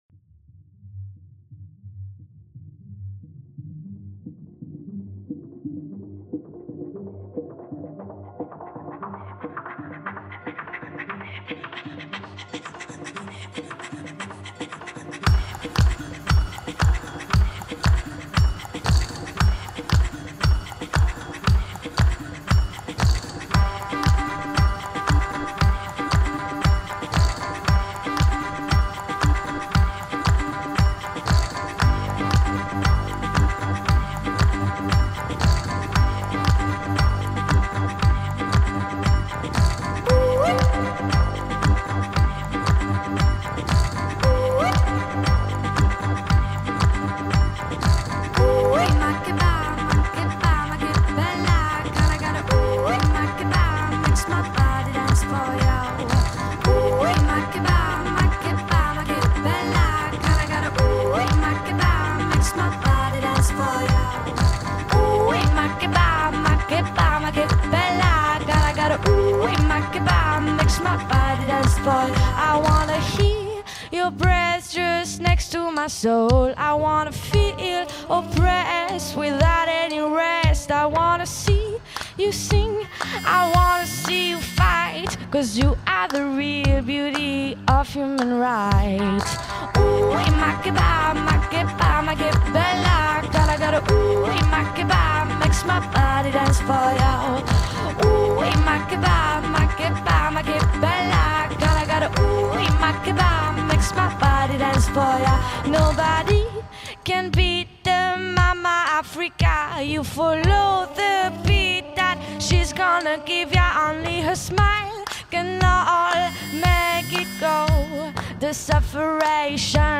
• Качество: 227 kbps, Stereo